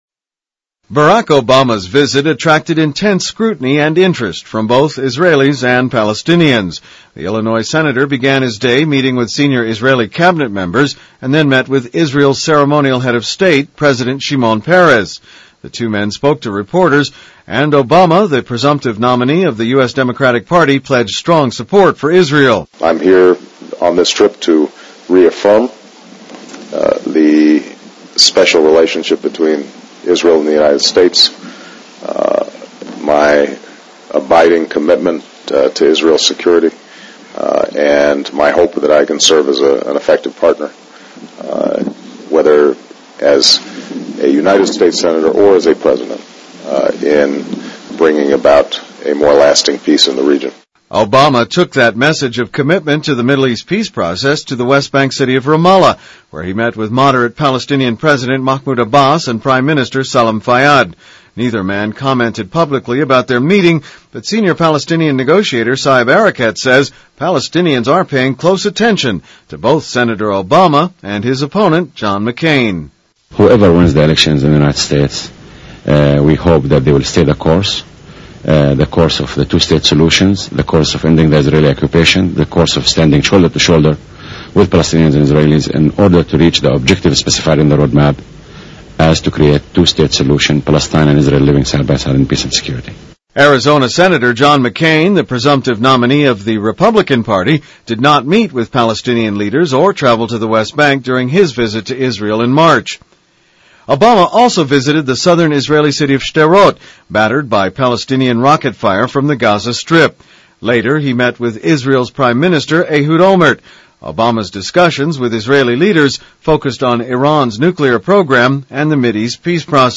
奥巴马旋风式访问以色列巴勒斯坦|英语新闻听力